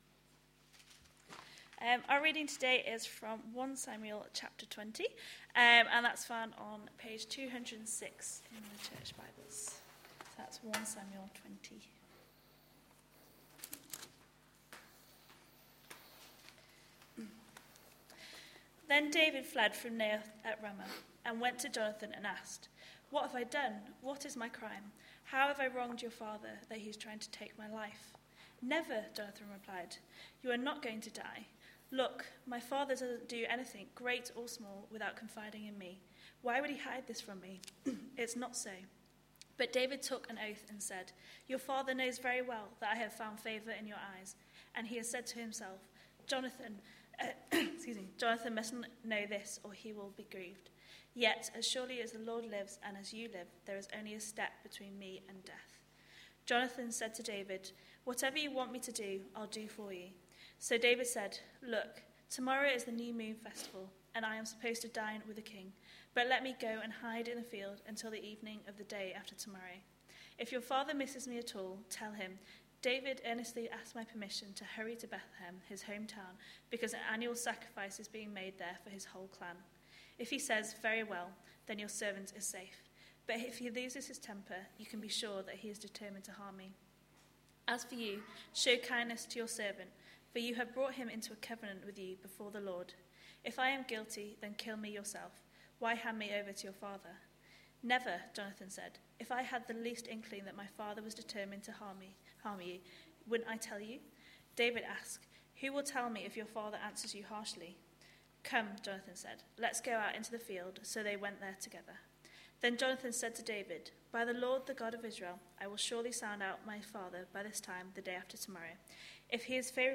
A sermon preached on 21st July, 2013, as part of our God's King? series.